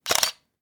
camera.mp3